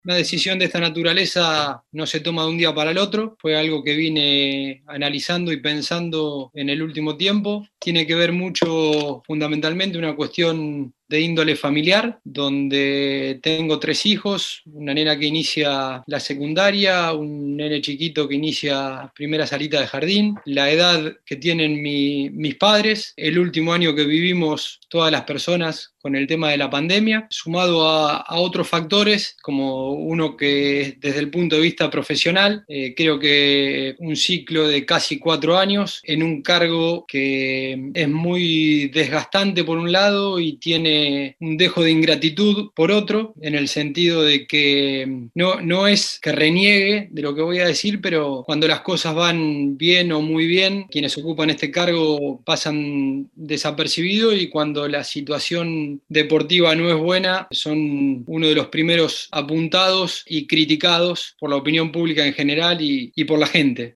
en conferencia de prensa